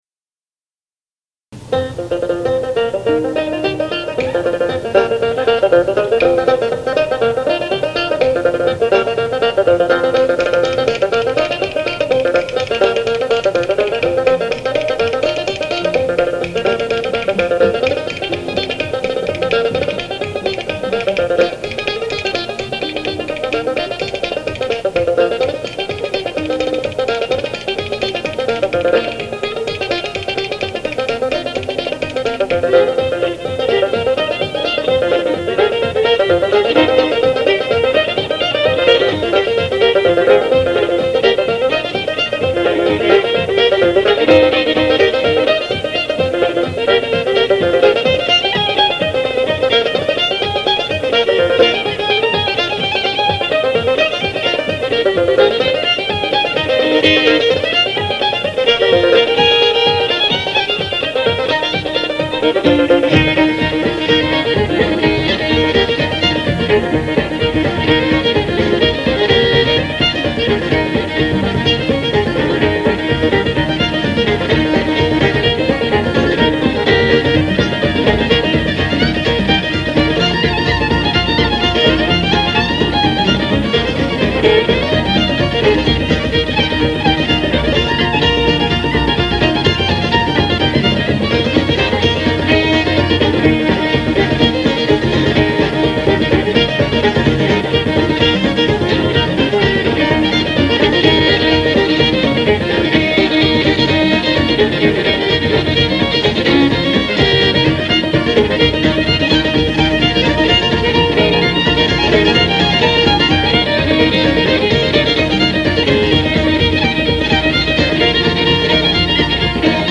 reels